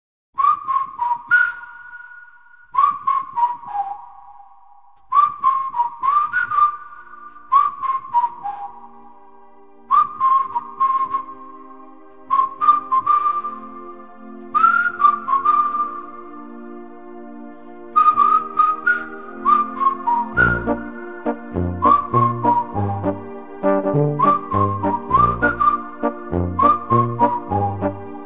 Gattung: Swing-Reggae
Besetzung: Blasorchester
in der Ausgabe für Blasmusik